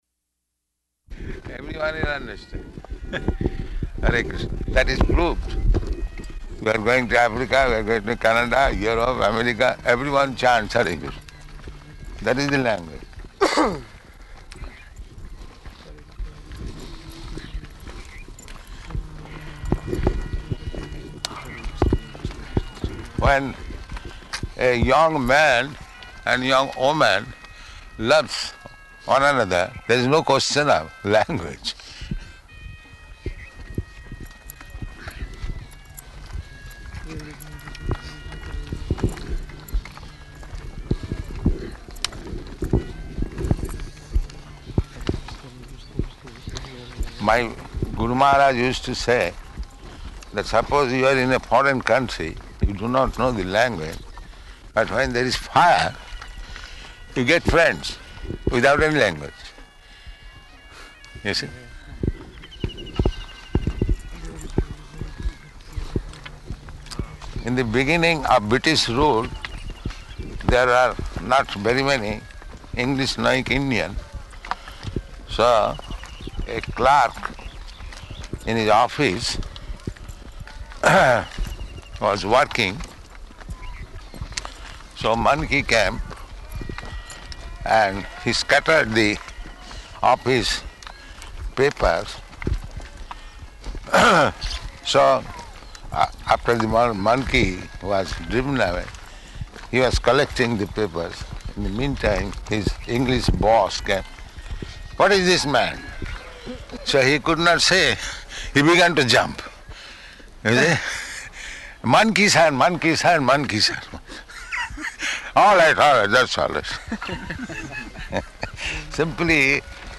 Morning Walk, [partially recorded]
Morning Walk, [partially recorded] --:-- --:-- Type: Walk Dated: March 7th 1974 Location: Māyāpur Audio file: 740307MW.MAY.mp3 Prabhupāda: Everyone will understand.